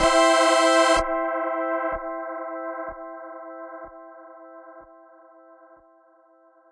各种原创声音 " 10合成器C5+ C6 - 声音 - 淘声网 - 免费音效素材资源|视频游戏配乐下载
使用FL Studio创建的声音，基本效果，其他插件